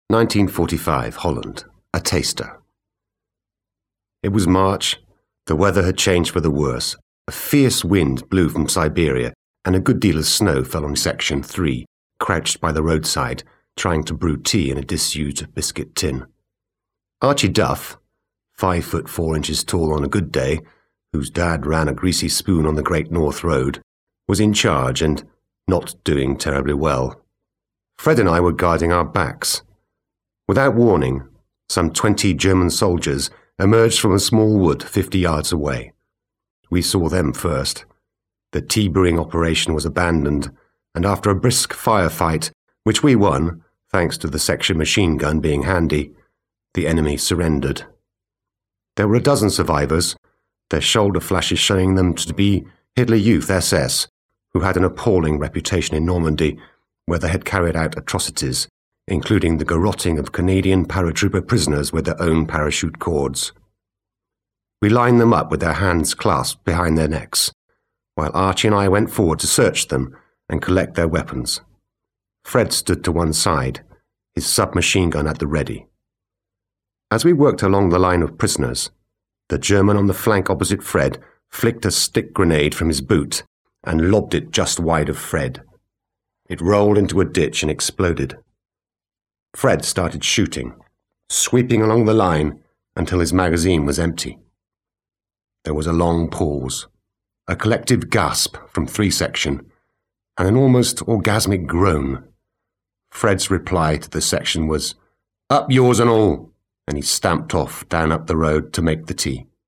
I've dabbled with some recording and it sounds very good.